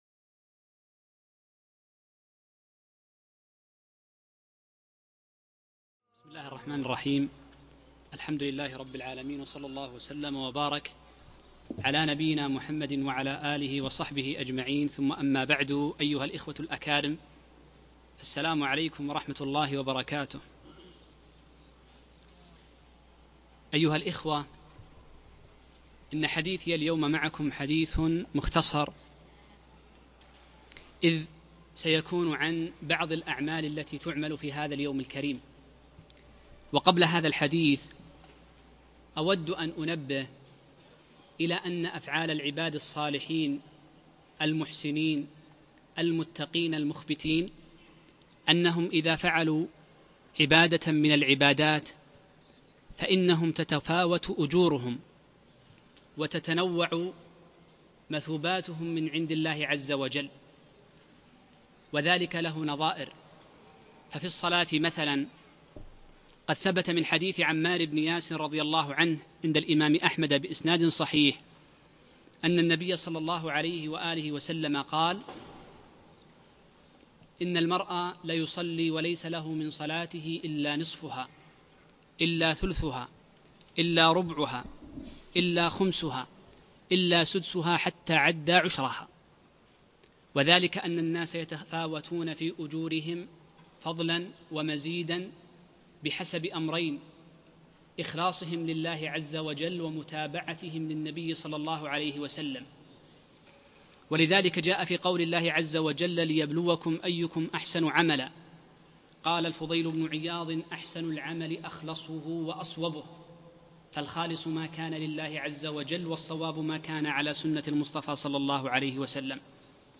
كلمة - أعمال تتأكد في رمضان